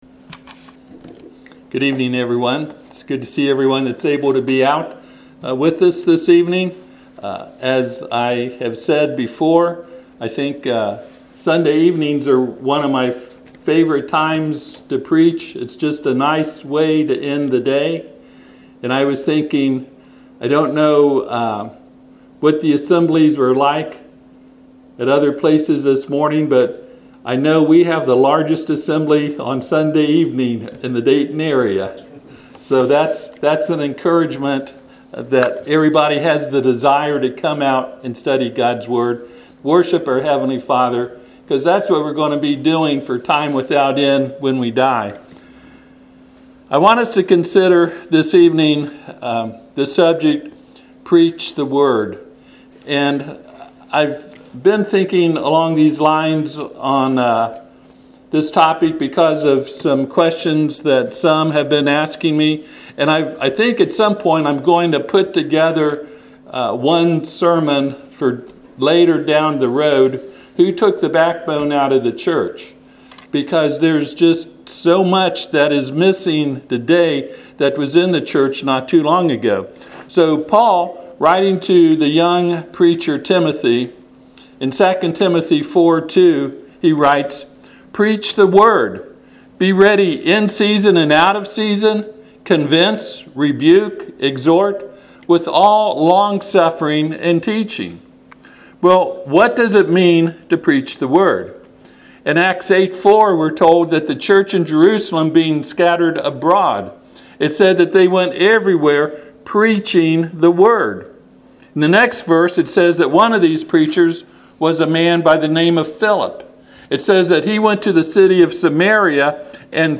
2 Timothy 4:2 Service Type: Sunday PM https